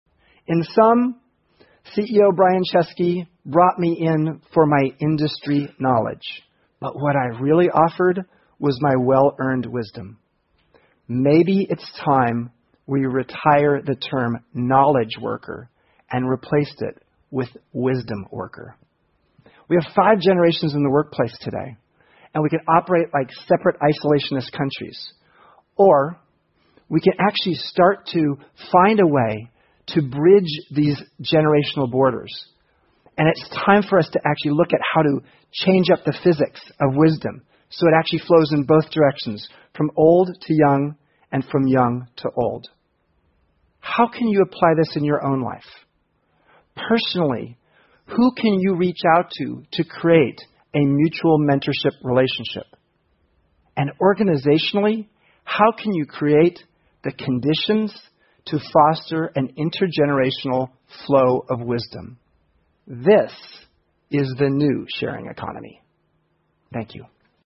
TED演讲:婴儿潮时期的人和千禧一代如何在工作中相互学习() 听力文件下载—在线英语听力室